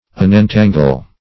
Unentangle \Un`en*tan"gle\, v. t.